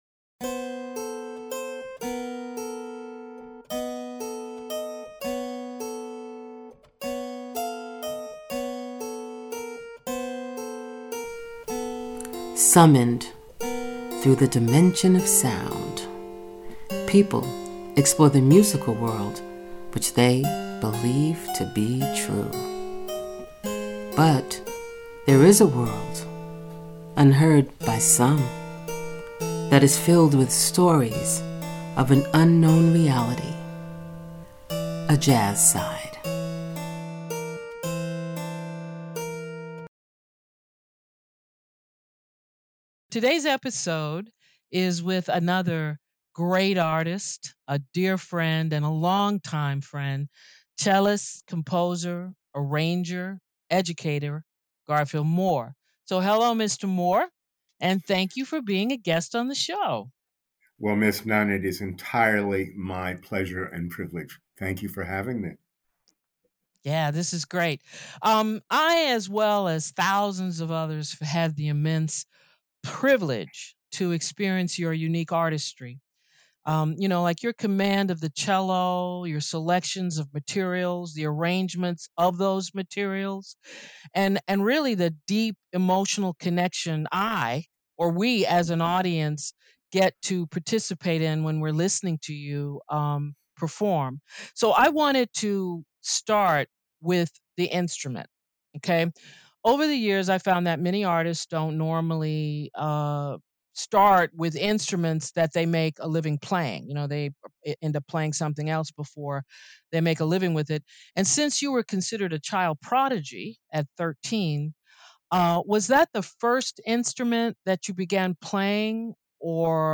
Tales is coming to you with guest vocalist/songwriter Sheila Jordan.